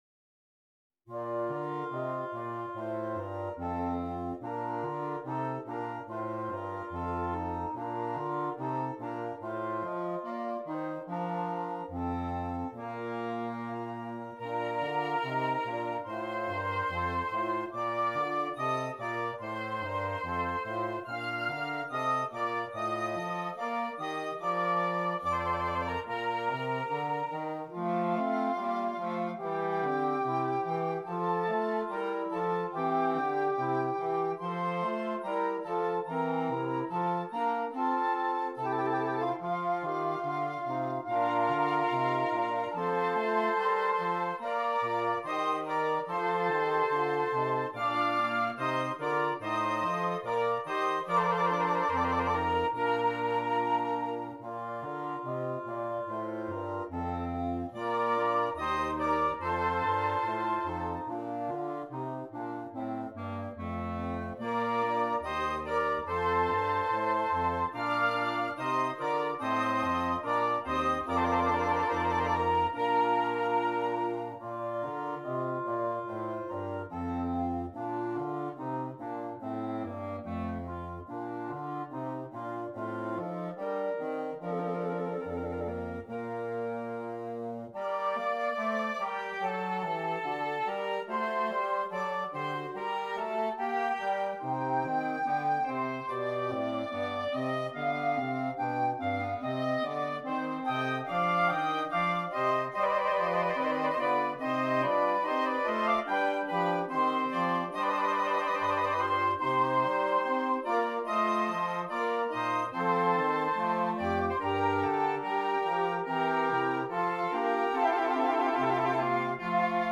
Interchangeable Woodwind Ensemble
This is the beautiful slow movement